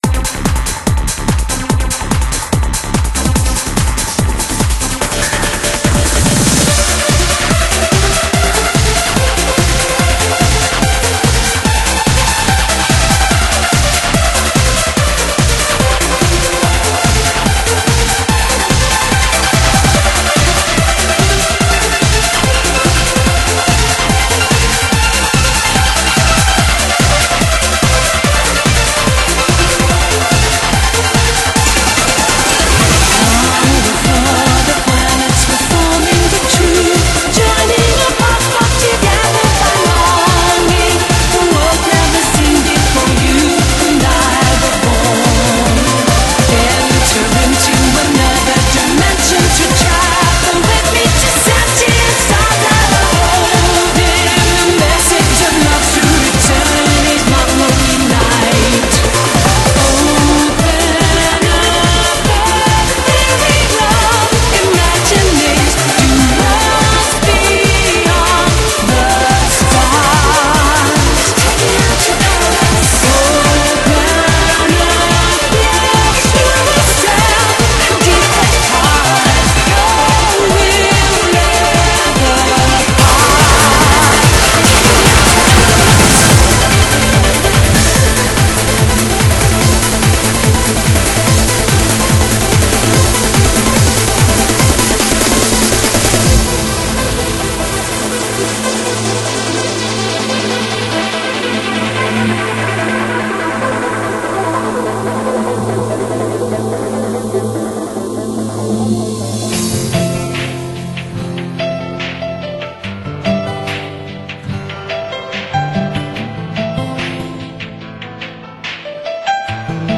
BPM70-280
Audio QualityPerfect (Low Quality)